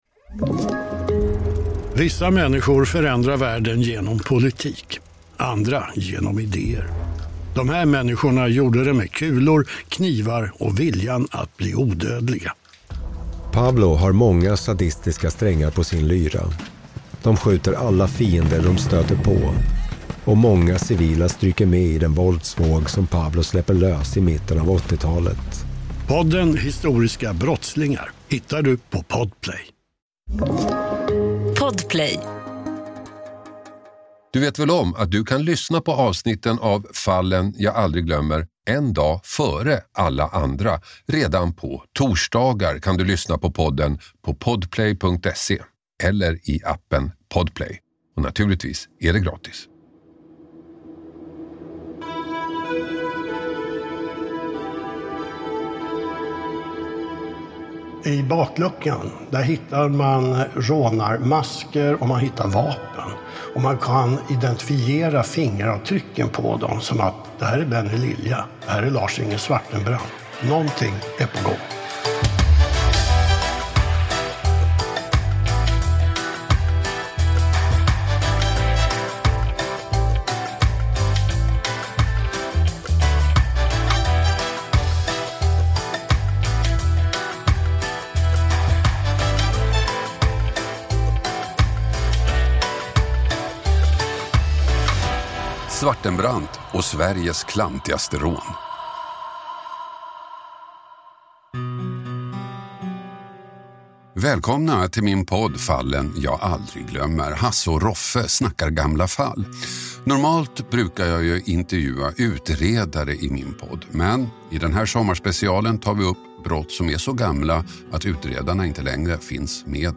intervjuar